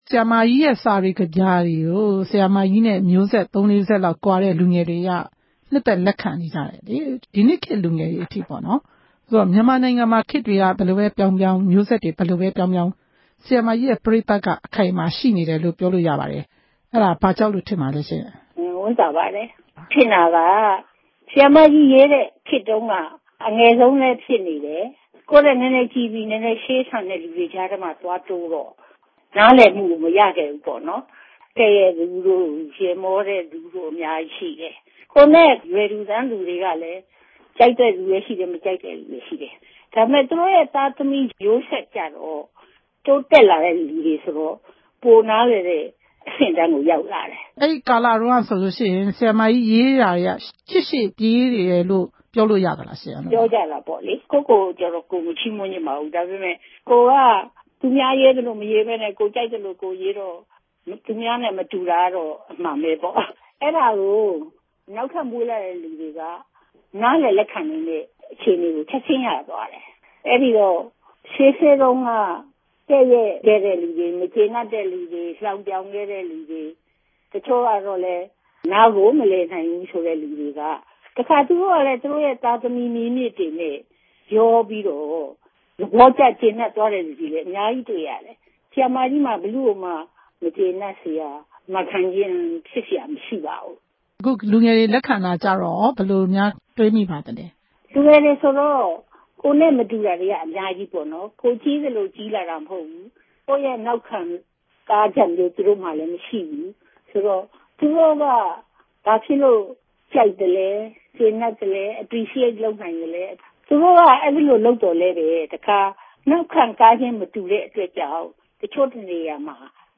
ဒေါ်ကြည်အေးနှင့် ဆက်သွယ်မေးမြန်းချက်